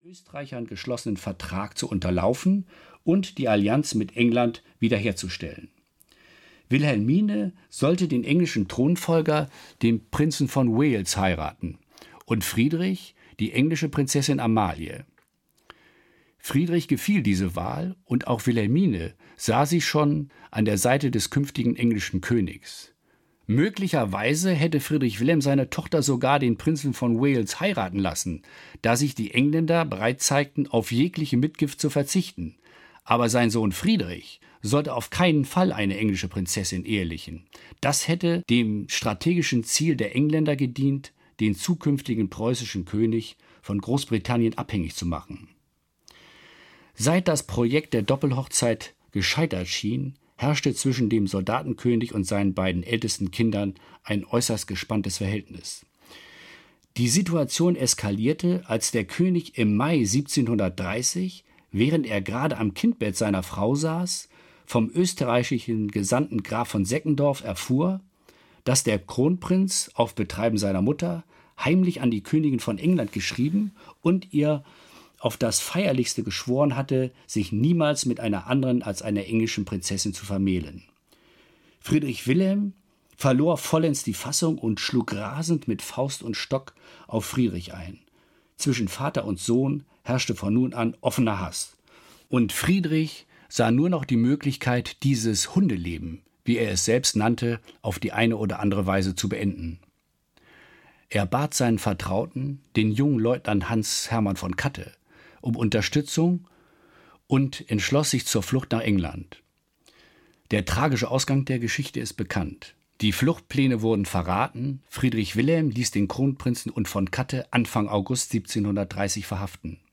Hörbuch Solange wir zu zweit sind, Max Volkert Martens.